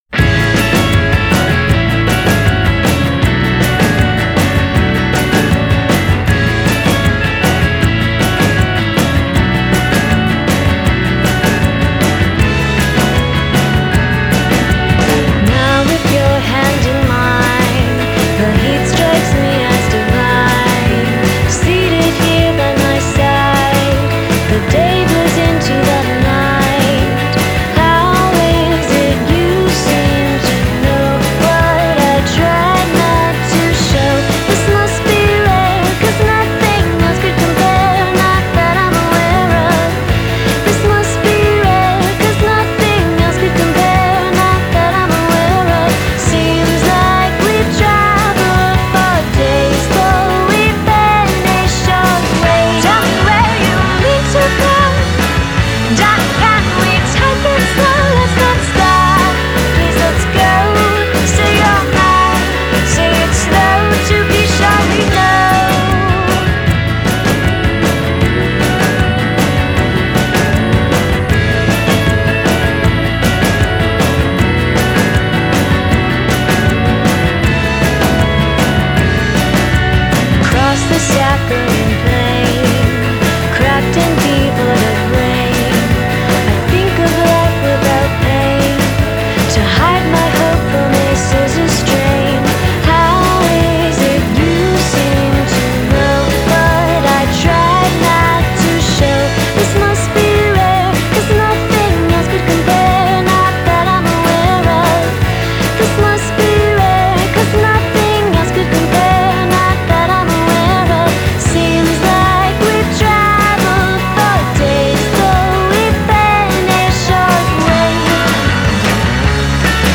Billie Holiday-esque coo
60’s garage psychedelia inspired production